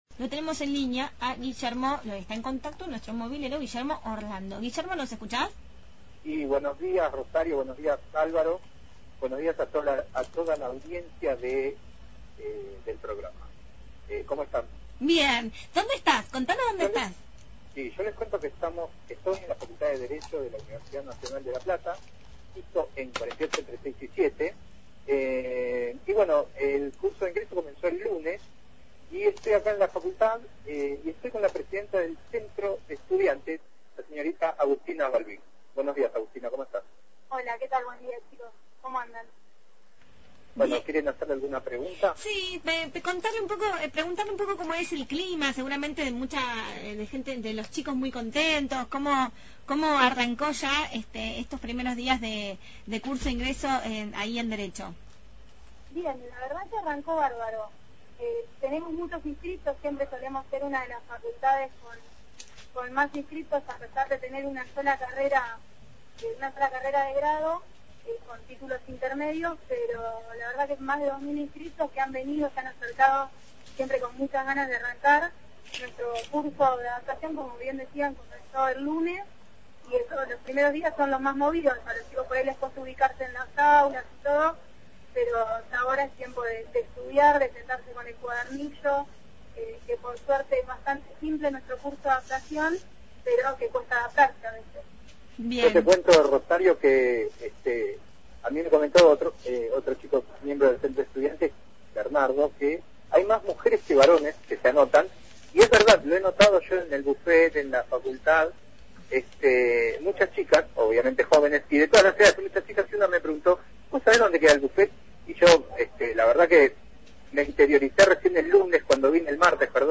MÓVIL/ Curso de ingreso Ciencias Jurídicas